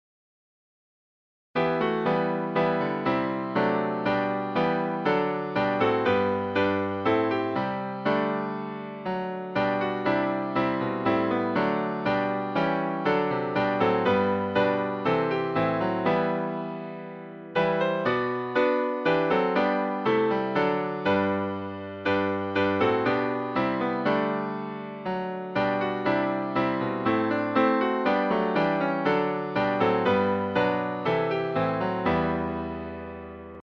Music by: English melody;